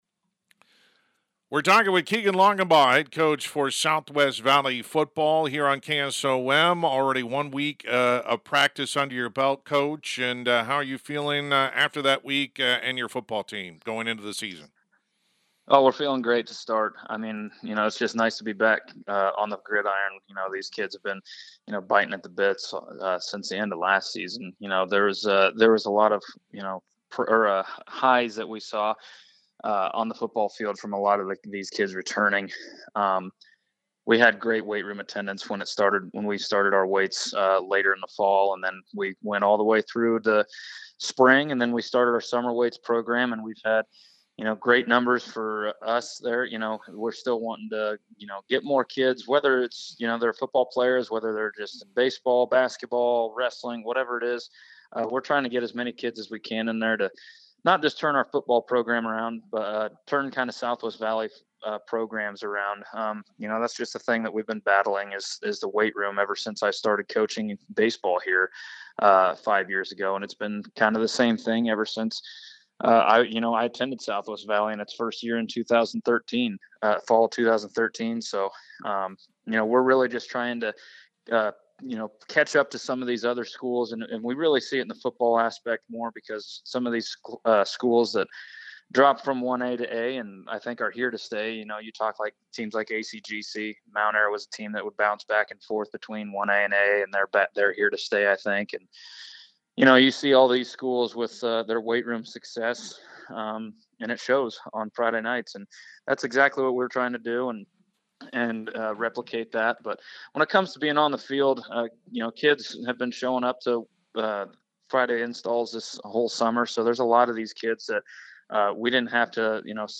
Complete Interview
southwest-valley-football-8-19.mp3